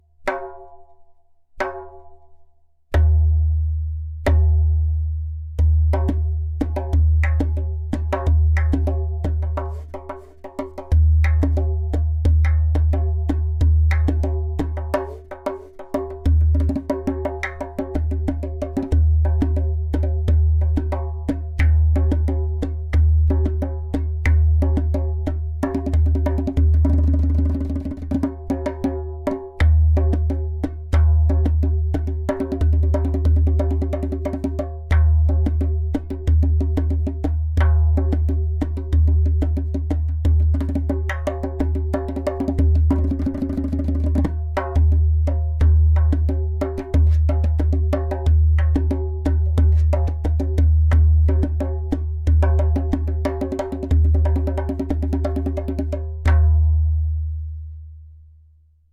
Premium True Bass Dohola
• Strong and super easy to produce clay kik (click) sound
• Very Deep bass
• Even tonality around edges.
• Beautiful harmonic overtones.
• Skin: Goat skin